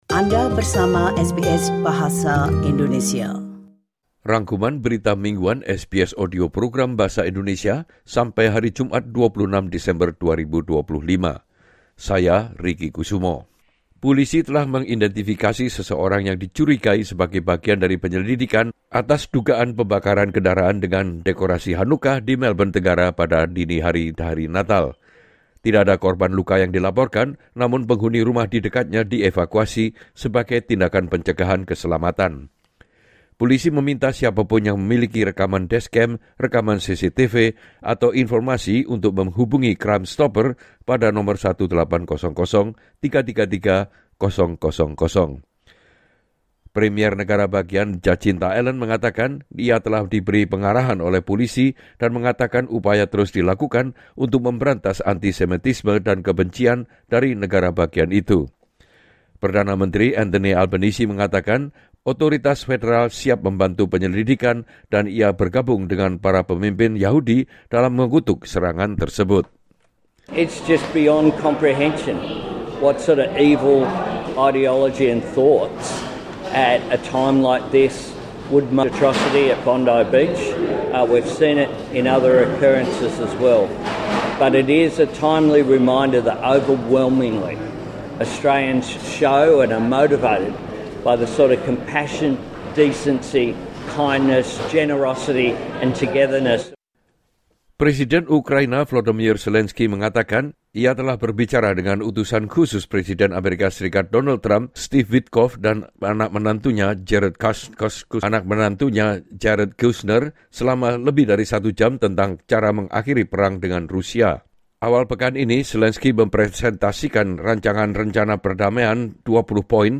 Rangkuman Berita Mingguan SBS Audio Program Bahasa Indonesia - Jumat 26 Desember 2025